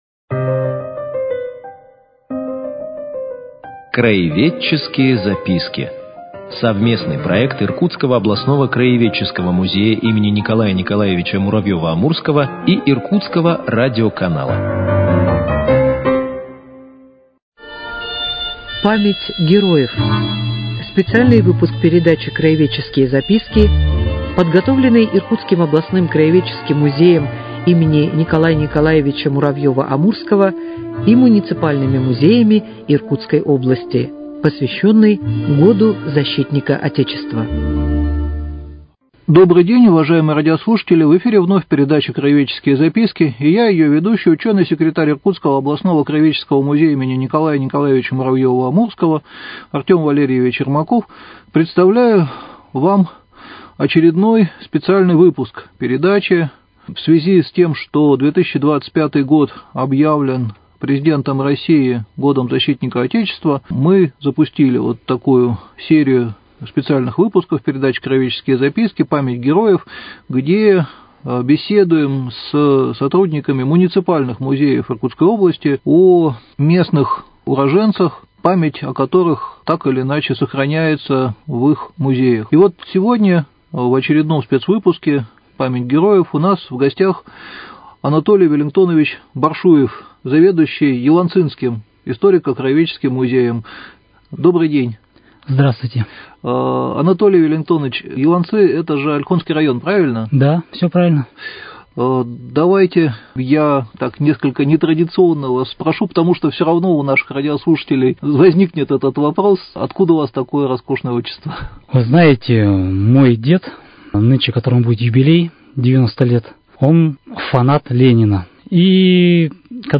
Цикл передач – совместный проект Иркутского радиоканала и Иркутского областного краеведческого музея им. Н.Н.Муравьёва - Амурского.